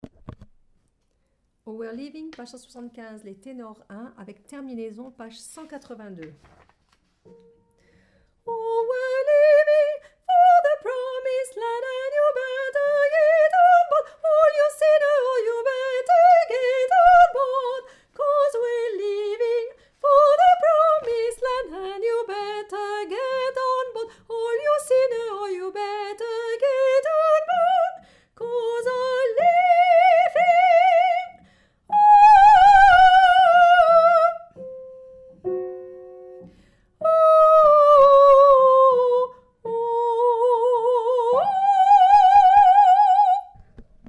Tenor1
oh-we-re-leavin_Tenor1.mp3